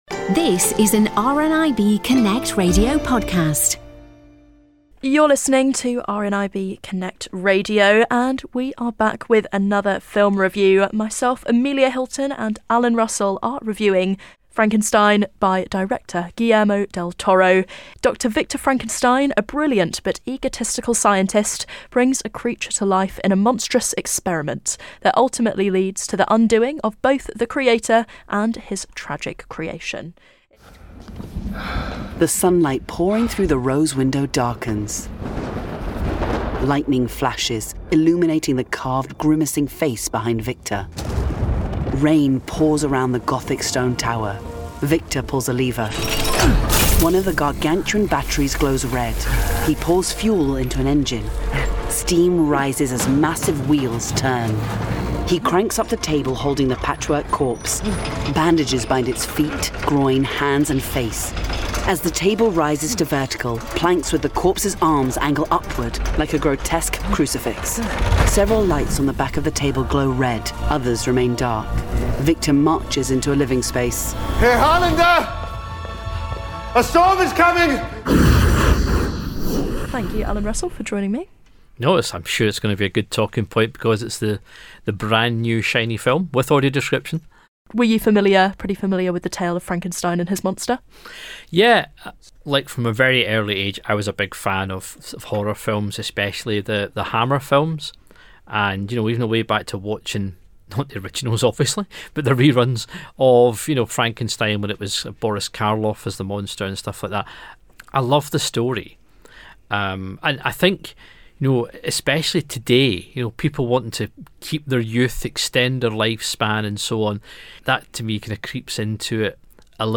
Film Review: Frankenstein 2025